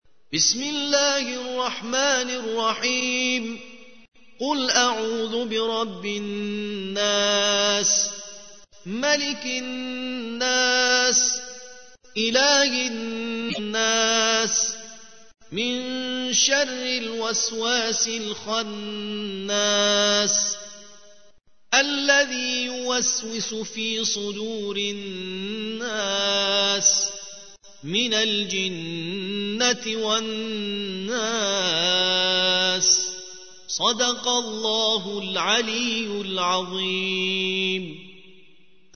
114. سورة الناس / القارئ